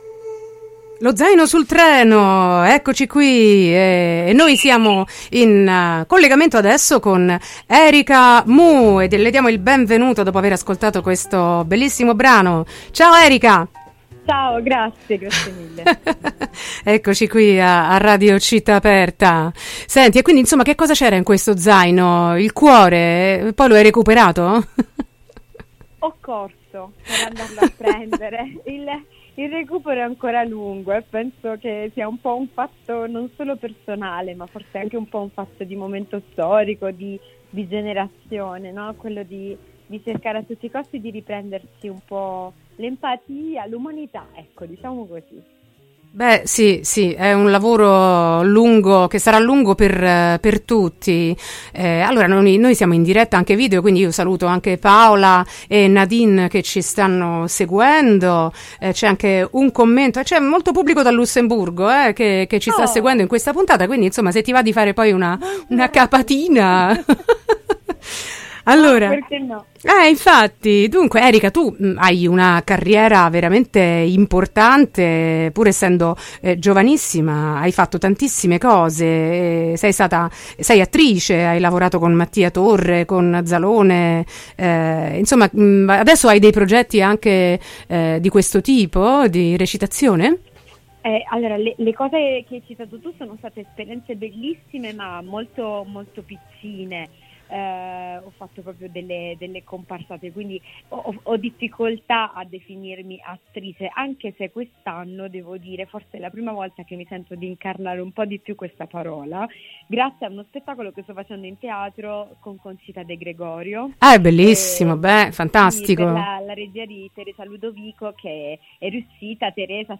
Le molteplici Nature del talento: intervista a Erica Mou | Radio Città Aperta